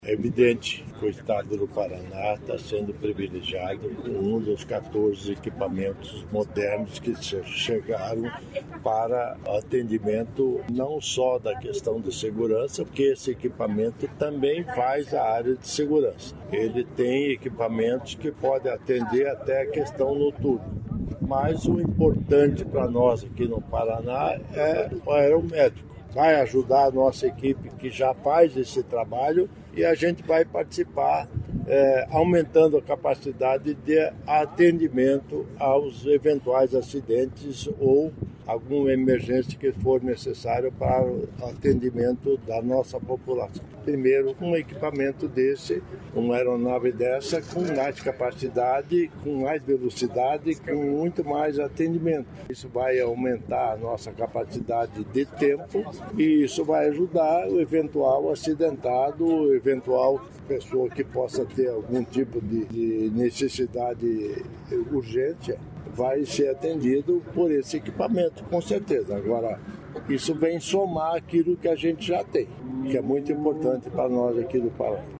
Sonora do governador em exercício Darci Piana sobre novo helicóptero da PRF que ficará à disposição para resgates aeromédicos na RMC e Litoral do Paraná